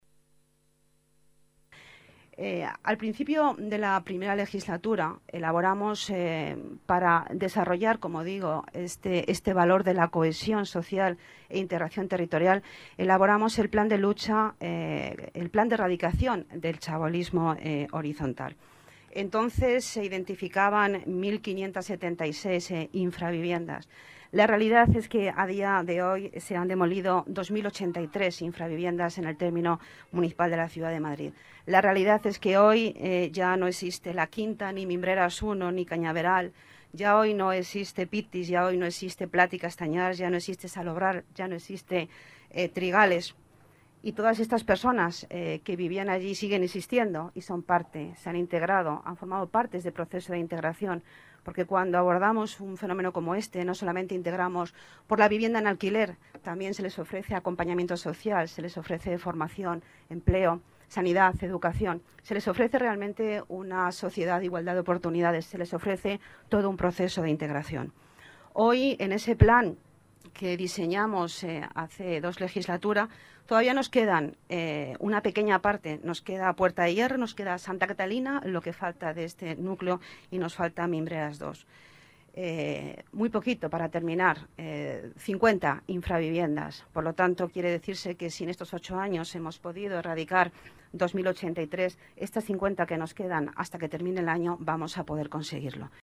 Nueva ventana:Declaraciones de Pilar Martínez, delegada de Urbanismo y Vivienda